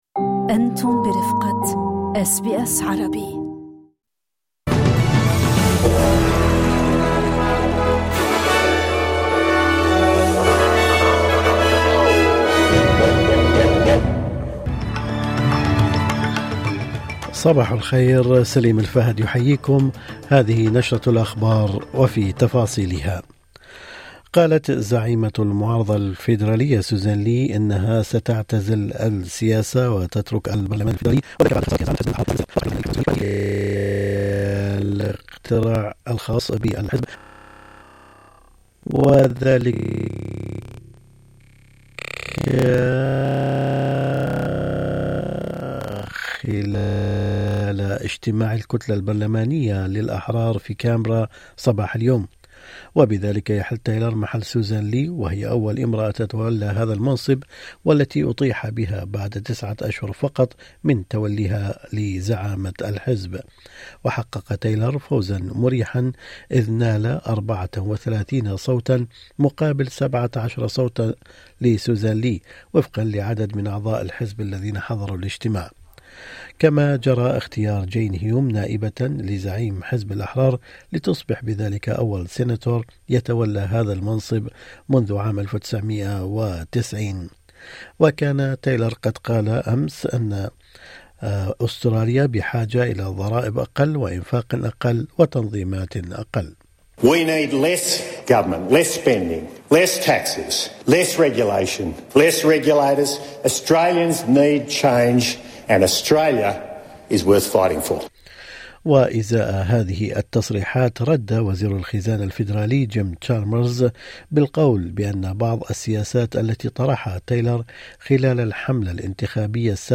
نشرة أخبار الظهيرة 13/02/2026
يمكنكم الاستماع الى النشرة الاخبارية كاملة بالضغط على التسجيل الصوتي أعلاه.